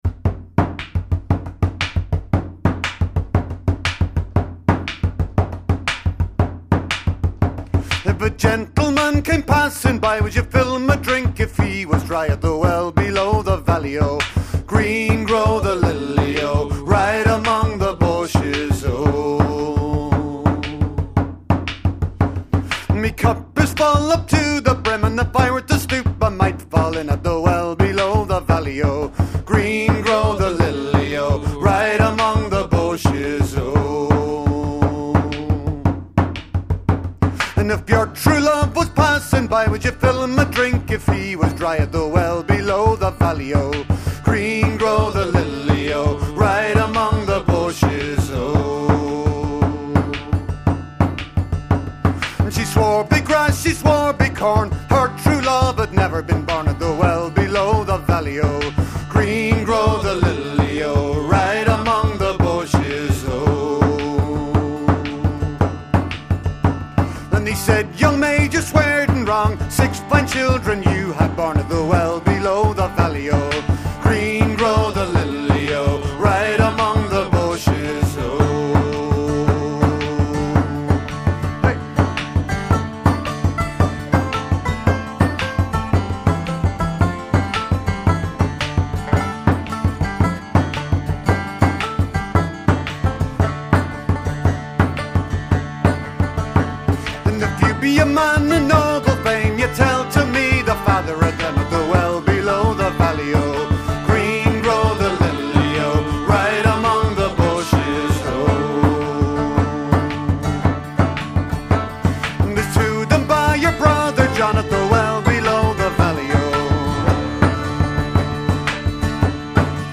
Recorded on a snowy night